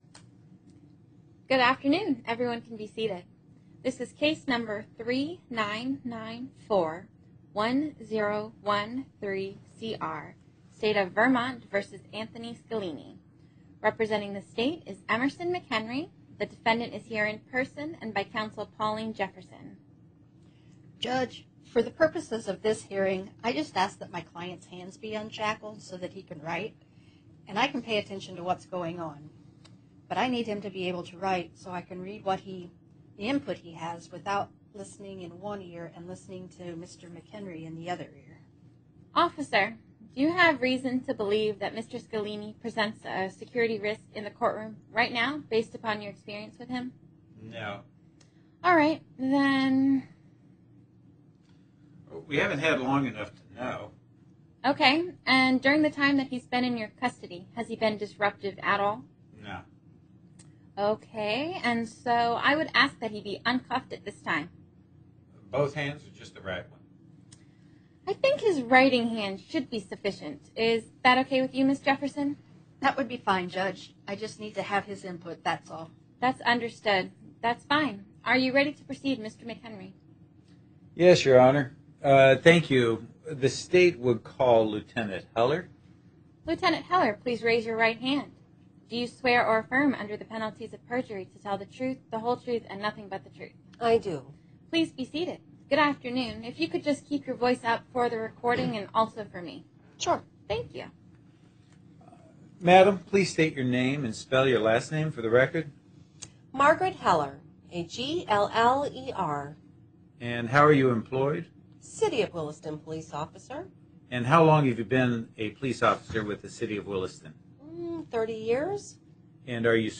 Recorded Conference Presentations
This practice audio is a 2-channel recording.